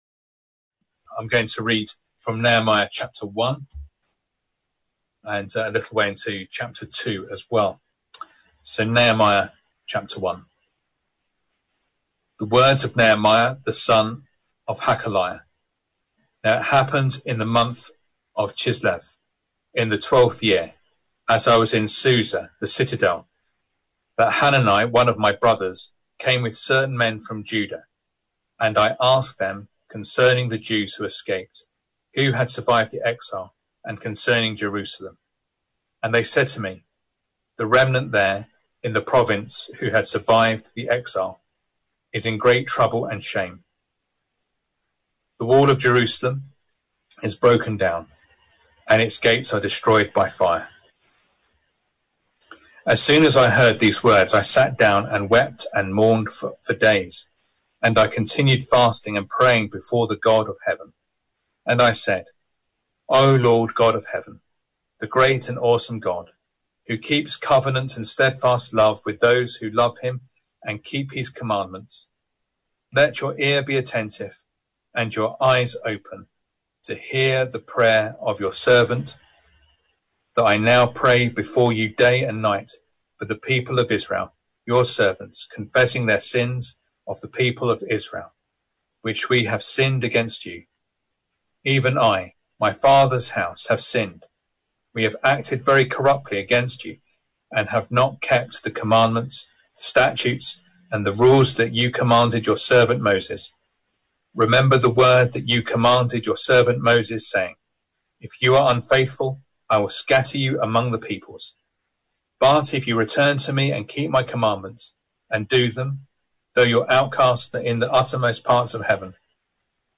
Service Type: Sunday Evening Reading and Sermon